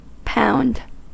Ääntäminen
Synonyymit pound Ääntäminen US Tuntematon aksentti: IPA : /paʊnd/ Haettu sana löytyi näillä lähdekielillä: englanti Käännöksiä ei löytynyt valitulle kohdekielelle.